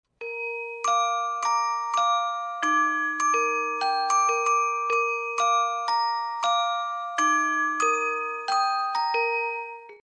Music Box Version